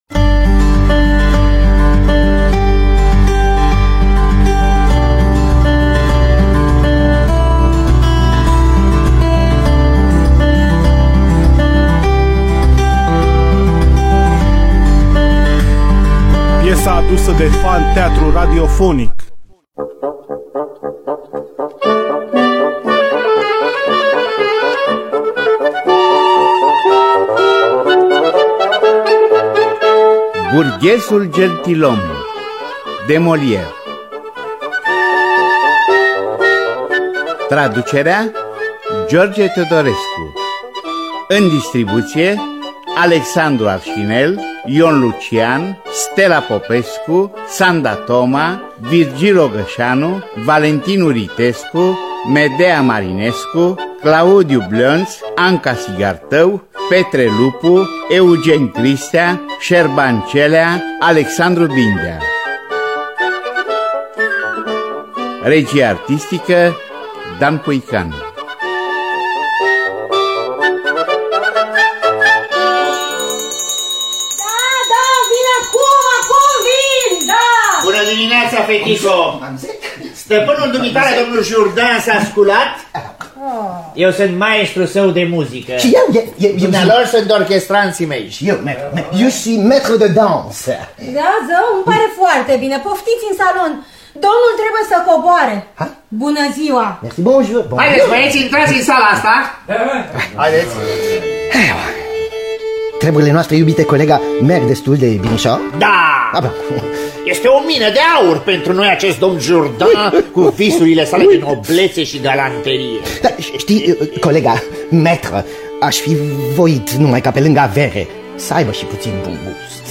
Burghezul gentilom de Jean-Baptiste Poquelin de Molière – Teatru Radiofonic Online